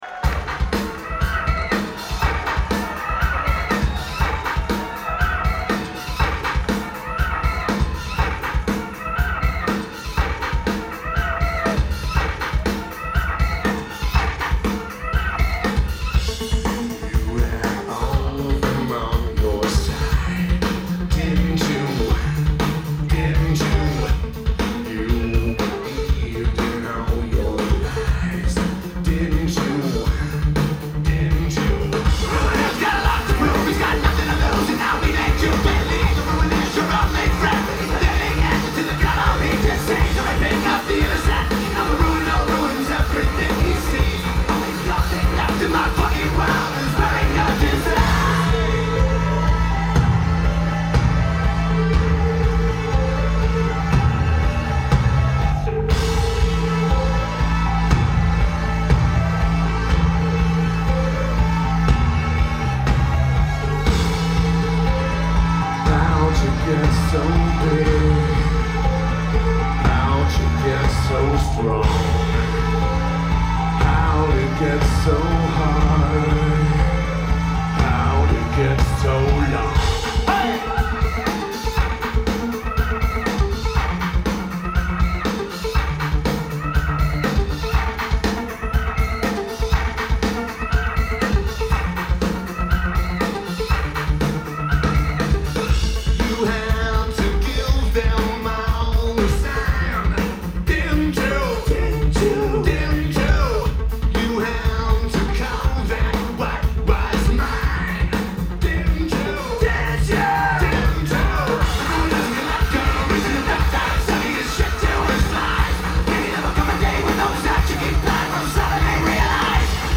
Echoplex
Los Angeles, CA United States
Lineage: Audio - AUD (MM-HLSC-1 + MM-MBM + Edirol R09)